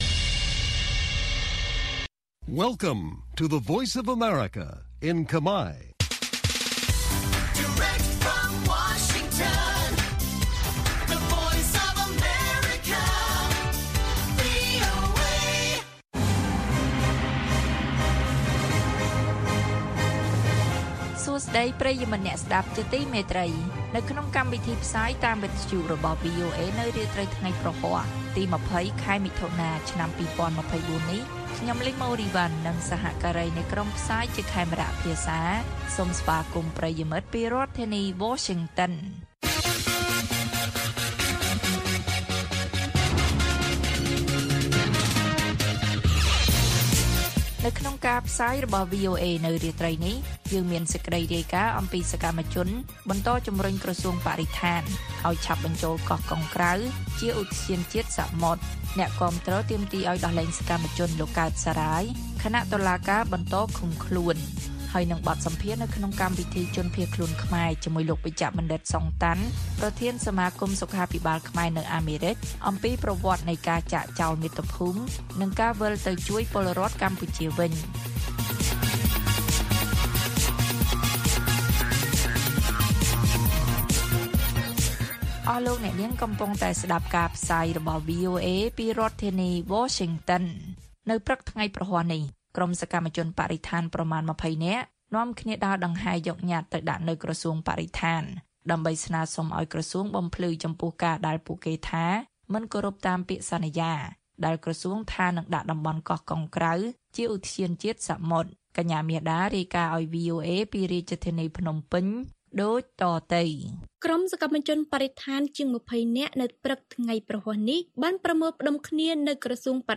ព័ត៌មានពេលរាត្រី ២០ មិថុនា៖ សកម្មជនបន្តជំរុញក្រសួងបរិស្ថានឱ្យឆាប់បញ្ចូលកោះកុងក្រៅជាឧទ្យានជាតិសមុទ្រ
ព័ត៌មាននៅថ្ងៃនេះមានដូចជា សកម្មជនបន្តជំរុញក្រសួងបរិស្ថានឱ្យឆាប់បញ្ចូលកោះកុងក្រៅជាឧទ្យានជាតិសមុទ្រ។ បទសម្ភាសន៍ VOA នៅក្នុងកម្មវិធីជនភៀសខ្លួនខ្មែរ៖ ជនរងគ្រោះនៃរបបខ្មែរក្រហមដែលរស់ជីវិតតែម្នាក់ឯងក្នុងមួយគ្រួសាររំឭកពីដំណើរចាកចោលមាតុភូមិ និងព័ត៌មានផ្សេងទៀត៕